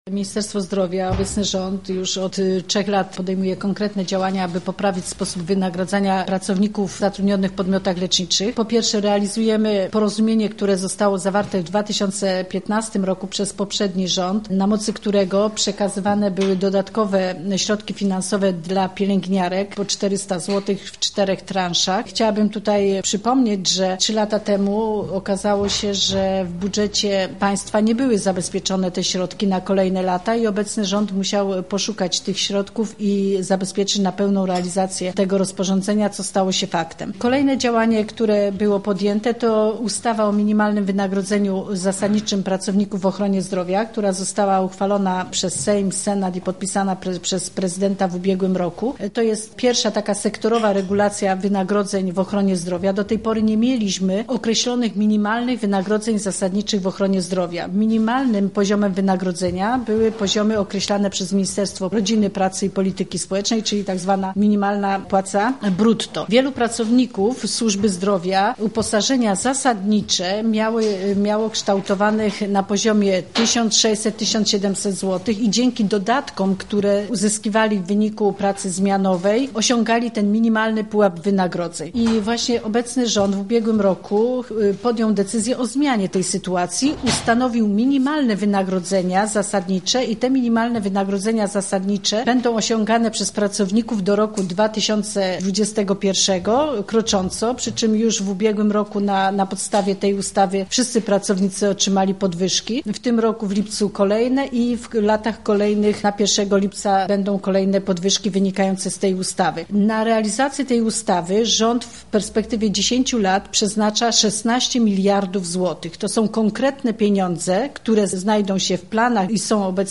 Sekretarz stanu w Ministerstwie Zdrowia, Józefa Szczurek-Żelazko podsumowała także działania obecnego rządu w kwestii poprawy wynagrodzenia pracowników zatrudnionych w podmiotach leczniczych i jak mówi: rząd traktuje służbę zdrowia priorytetowo: